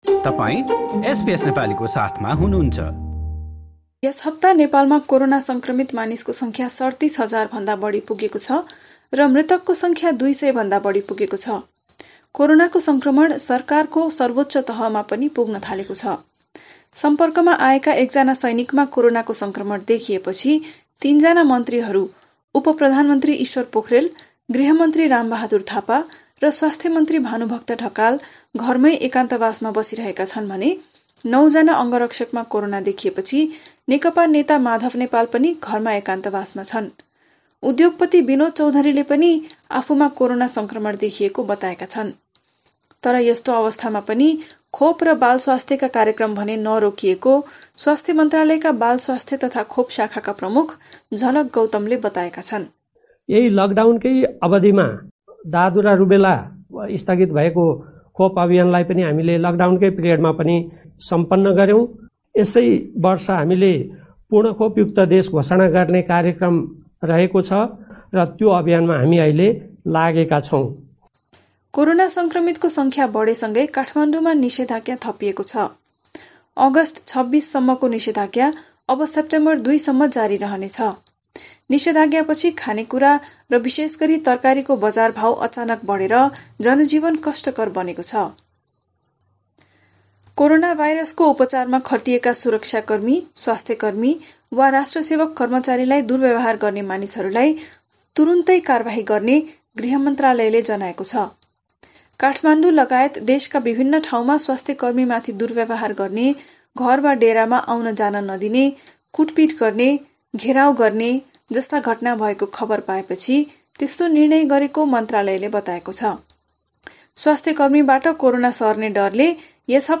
A text version of this news report is available in the Nepali language version of our website.